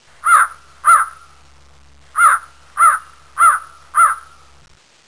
crow1.wav